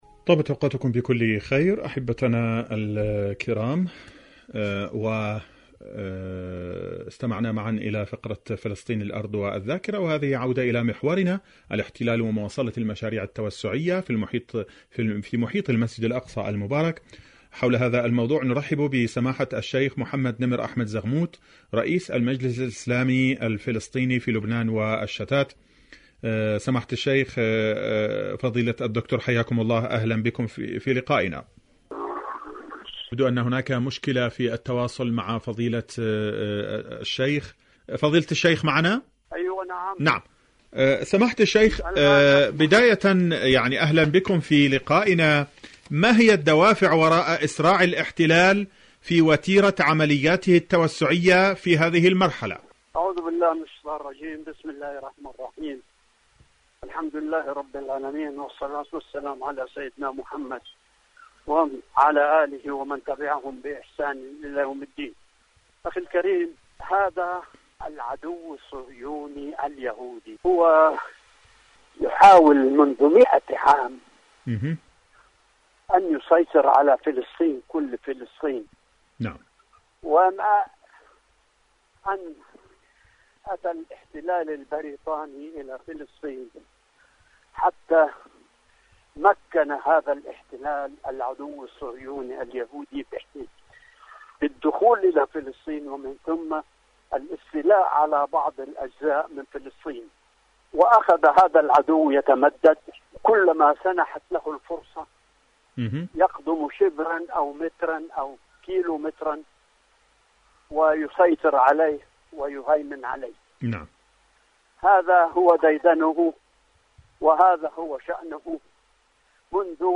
إذاعة طهران-فلسطين اليوم: مقابلة إذاعية